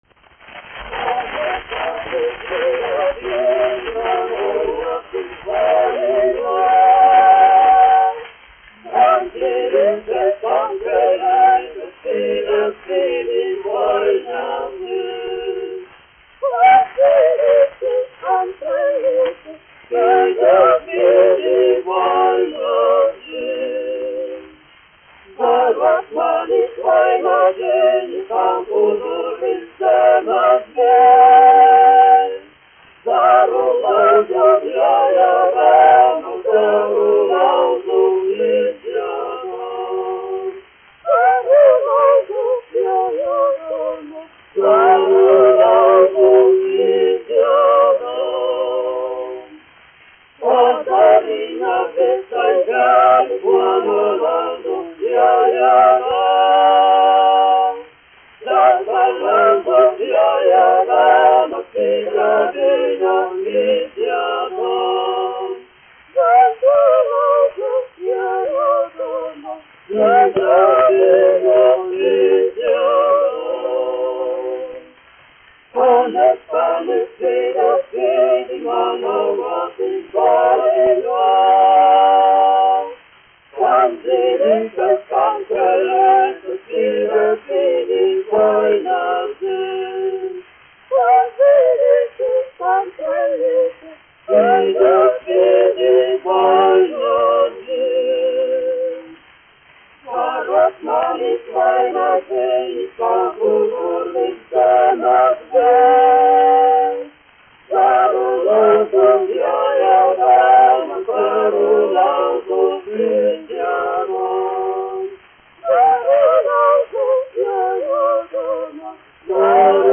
1 skpl. : analogs, 78 apgr/min, mono ; 25 cm
Vokālie kvarteti
Skaņuplate